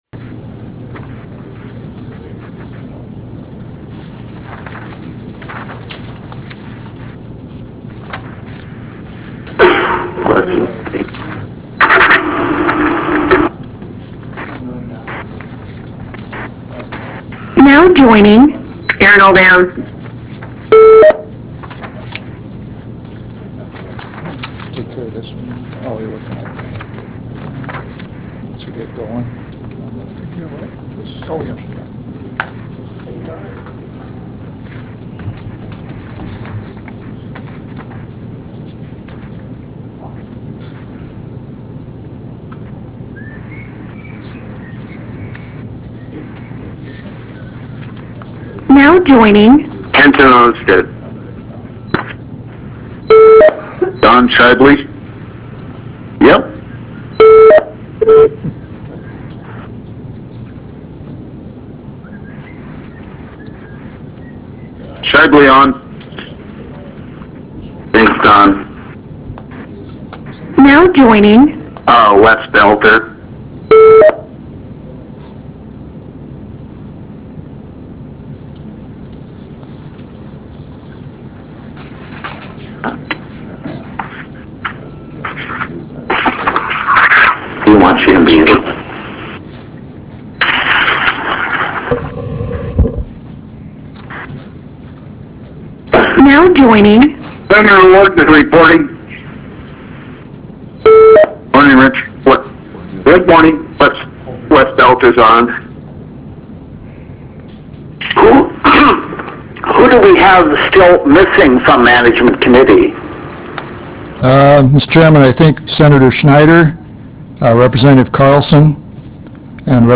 This meeting will be conducted by teleconference call connection.
Harvest Room State Capitol Bismarck, ND United States